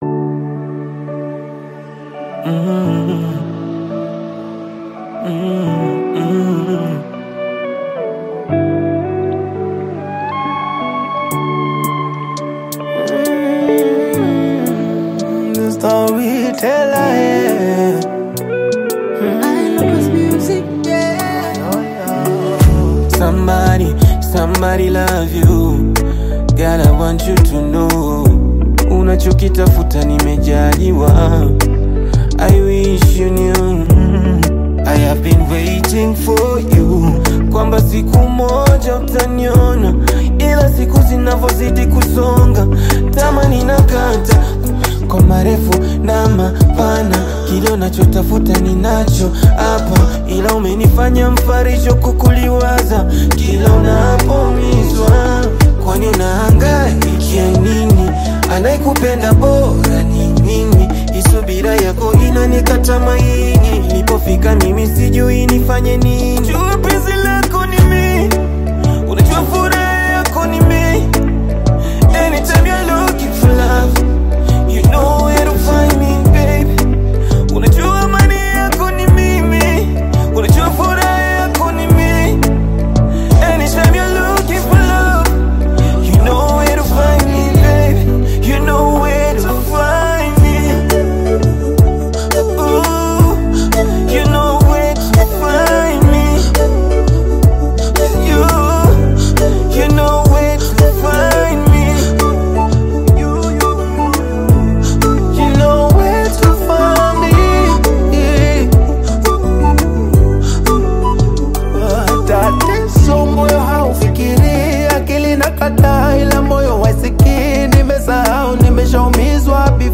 known for his smooth R&B vocals and heartfelt lyrics